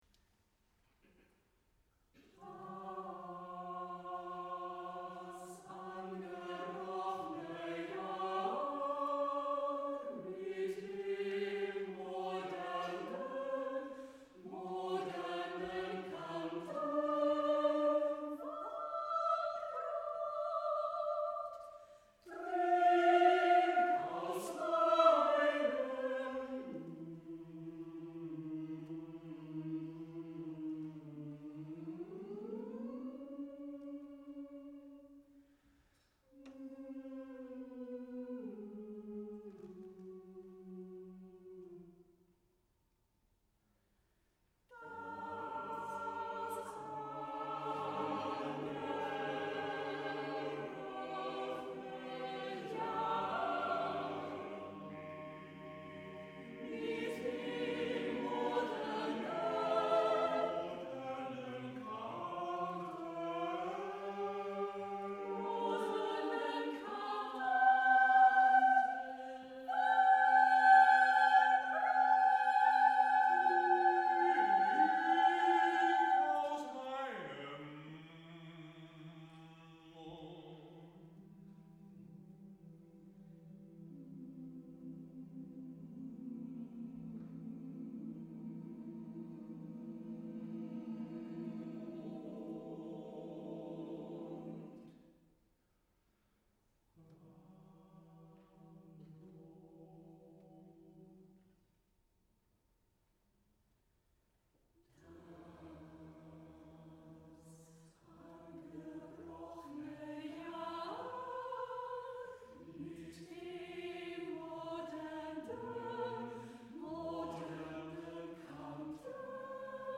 SECHS CHORSTUCKE
SATB
Live recording.